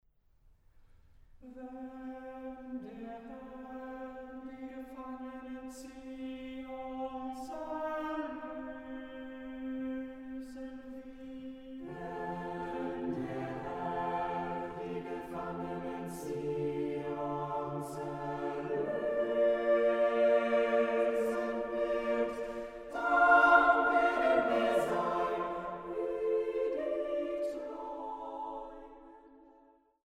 Rekonstruktion eines Synagogenkonzertes aus Leipzig von 1926